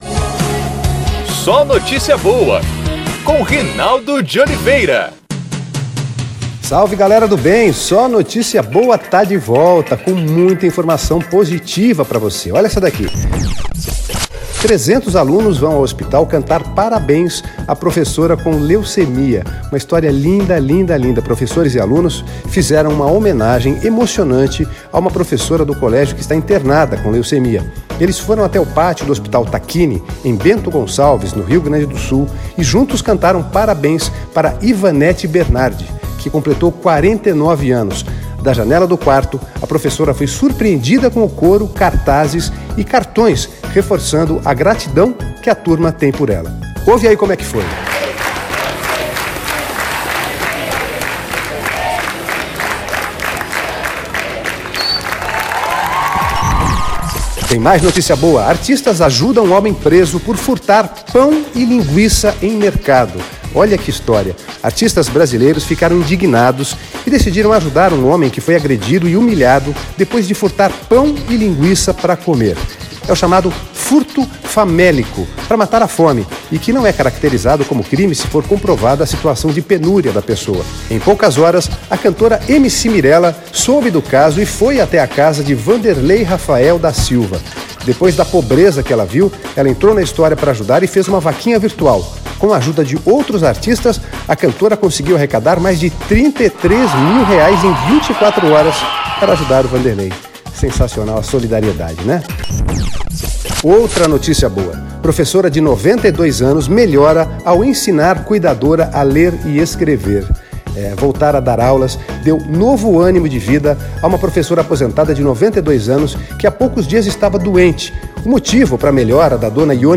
Também é exibido em pílulas na programação da Rádio Federal.